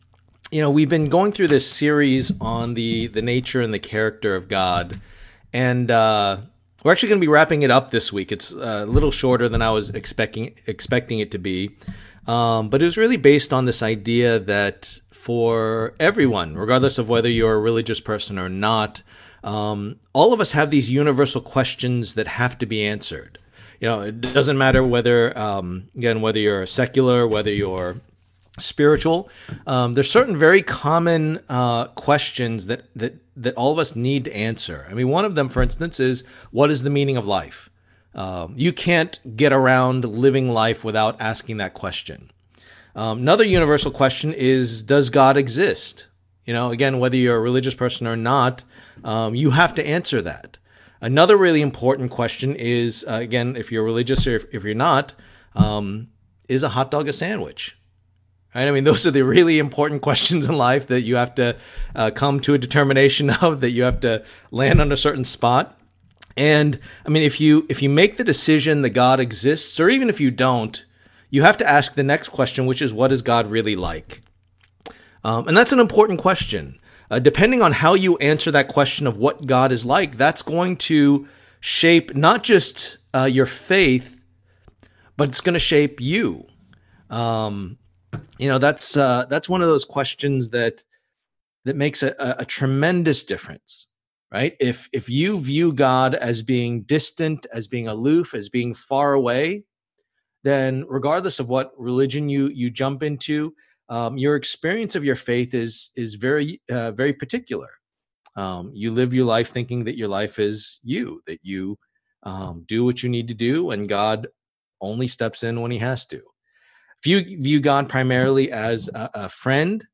Passage: 1 John 4:7-21 Service Type: Lord's Day